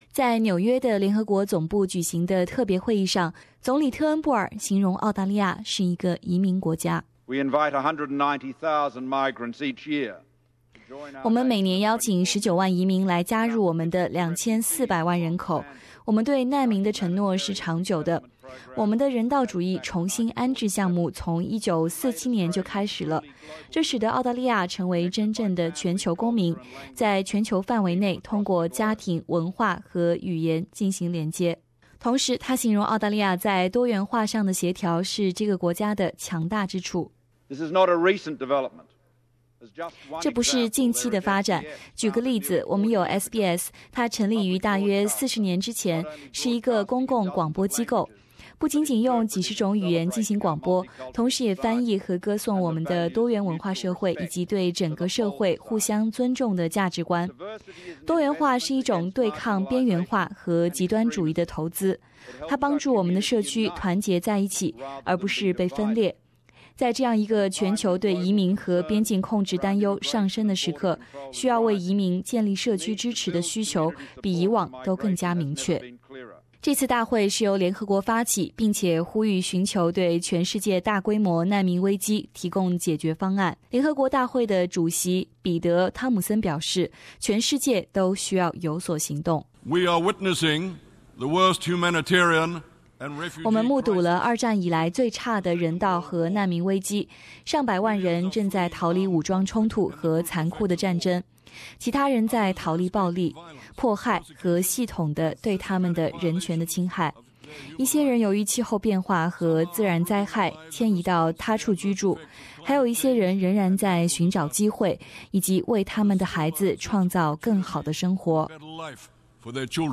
总理特恩布尔在联合国大会上发表了演讲，称赞澳大利亚的多元文化是这个国家的强项之一，并且呼吁国际社会对多元化进行投资,并表示这可以对抗边缘化和极端 主义。
Prime Minister Malcolm Turnbull speaks during the Summit for Refugees and Migrants at UN headquarters in NY Source: AAP